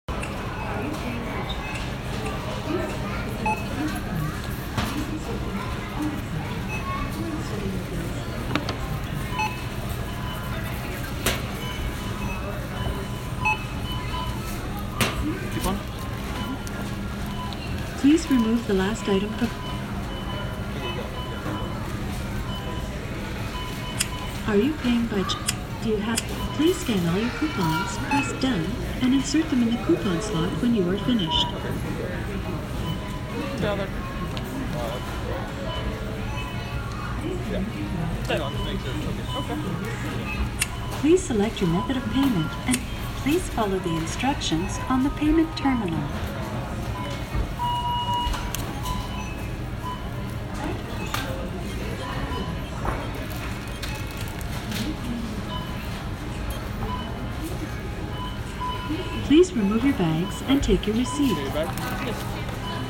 self check-out in a local grocery store
these have a nice voice but unlike those in target i don't think you can make them tell the price wit voice